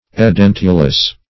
Search Result for " edentulous" : Wordnet 3.0 ADJECTIVE (1) 1. having lost teeth ; The Collaborative International Dictionary of English v.0.48: Edentulous \E*den"tu*lous\ ([-e]*d[e^]n"t[-u]*l[u^]s; 135), a. [L. edentulus; e out + dens, dentis, tooth.]